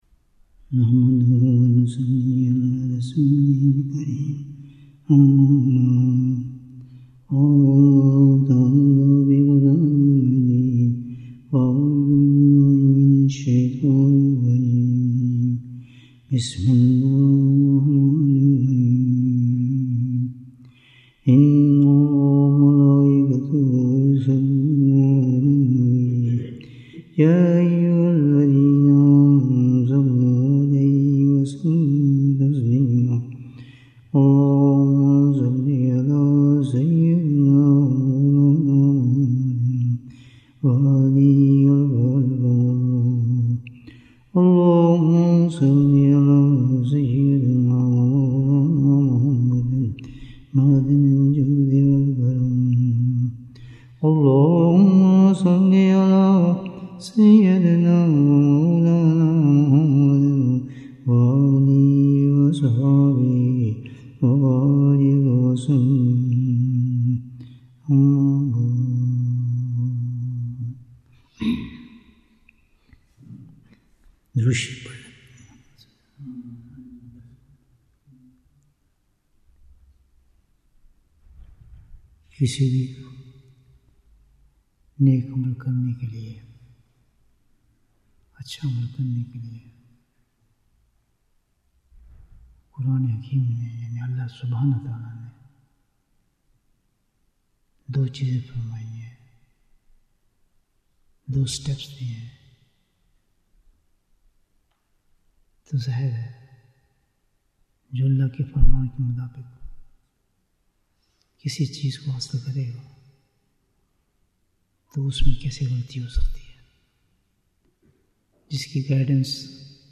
Bayan, 80 minutes 25th September, 2025 Click for English Download Audio Comments What are the Two Points for Success?